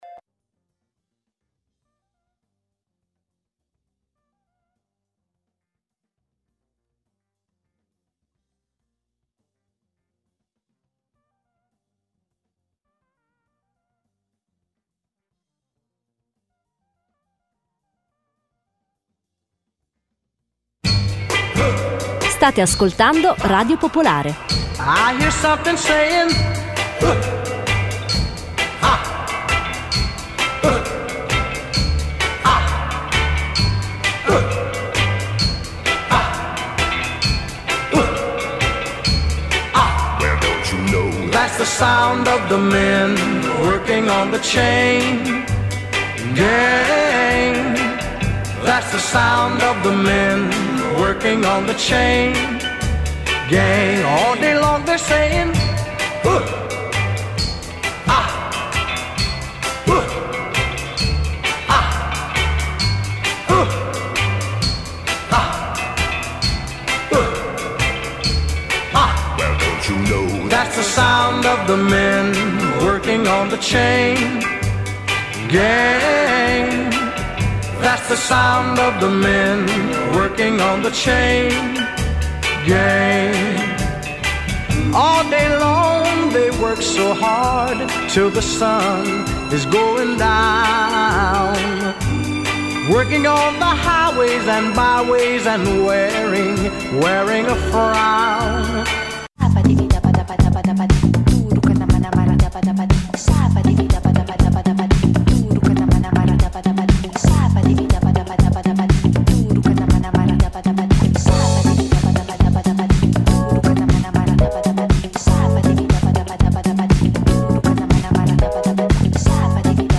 Almendra è una trasmissione estiva di Radio Popolare in cui ascoltare tanta bella musica, storie e racconti da Milano e dal mondo, e anche qualche approfondimento (senza esagerare, promesso).